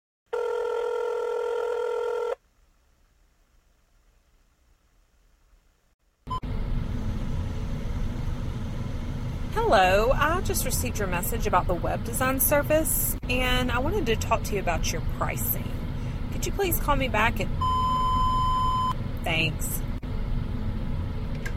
The Voice Lead Generator drops your promo message right into voicemail boxes, no cold calling needed!